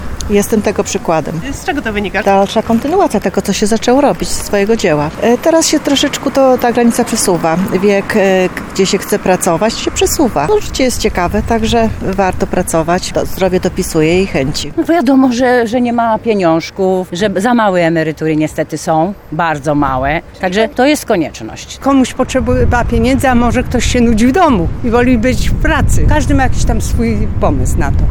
Mieszkańcy Tarnowa wskazują na różne przyczyny takiego stanu.